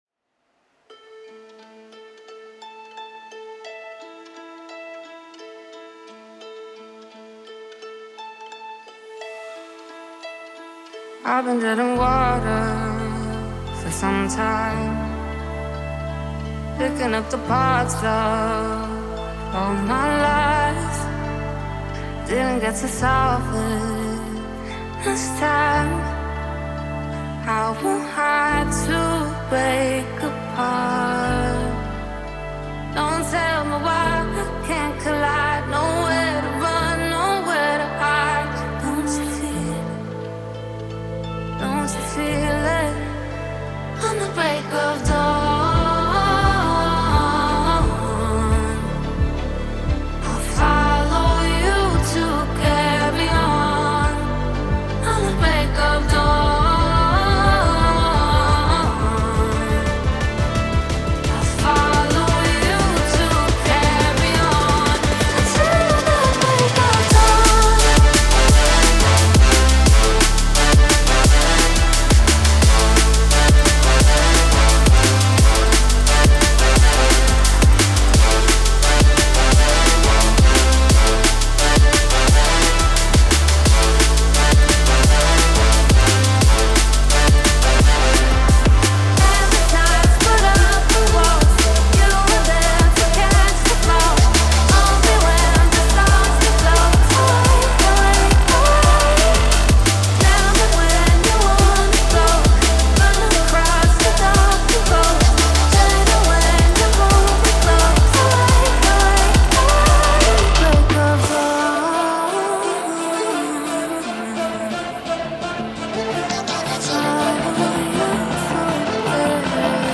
energetic, positive, powerful